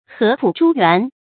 注音：ㄏㄜˊ ㄆㄨˇ ㄓㄨ ㄏㄨㄢˊ
合浦珠還的讀法